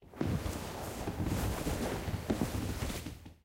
anonBedRustle.ogg